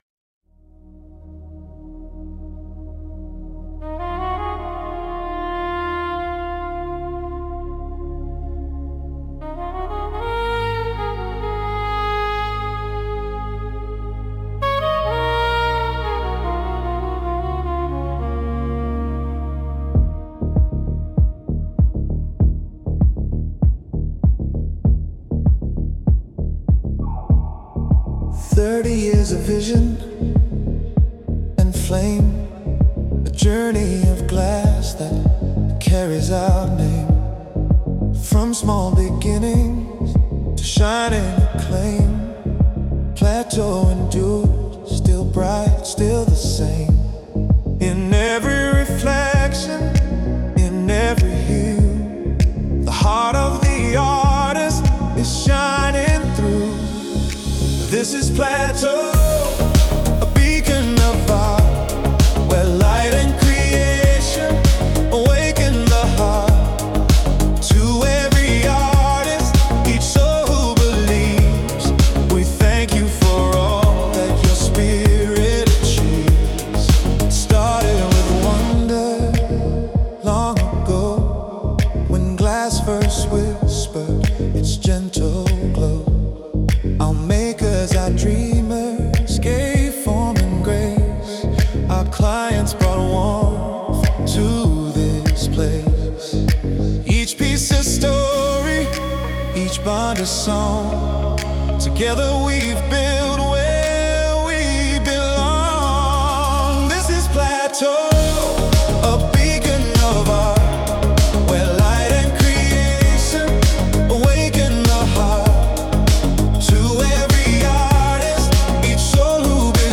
A polished, celebratory anthem for a major milestone.